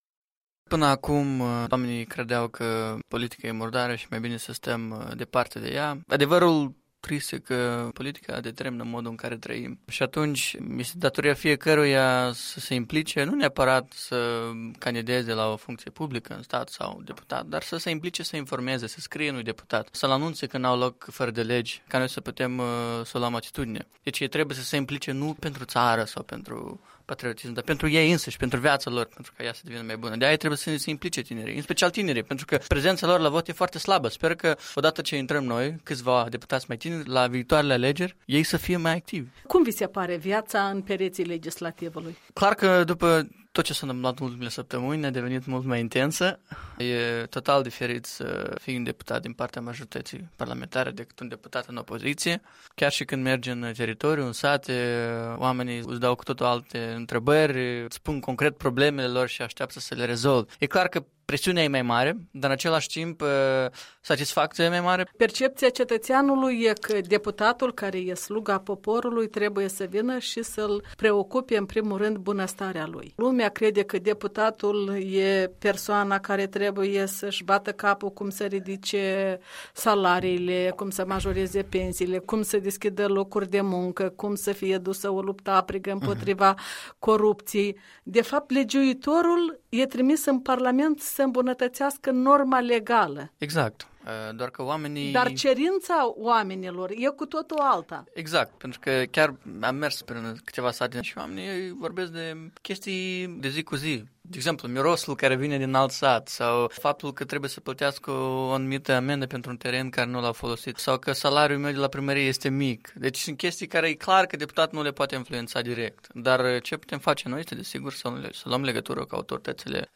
Un interviu cu deputatul din fracțiunea Partidul Acțiune și Solidaritate, Blocul ACUM.